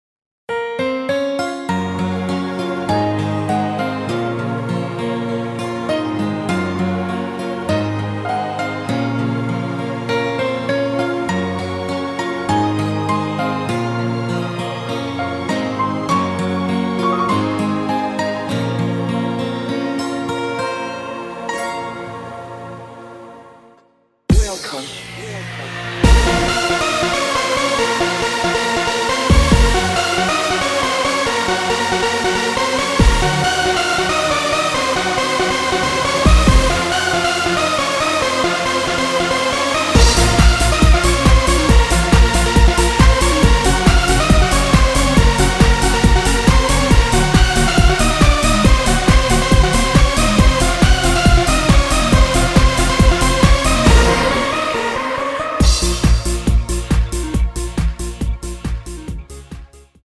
(-2) 내린 MR 입니다.(미리듣기 참조)
Bbm
앞부분30초, 뒷부분30초씩 편집해서 올려 드리고 있습니다.
중간에 음이 끈어지고 다시 나오는 이유는